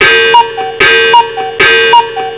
VOCES
SONIDO PARA MENSAJES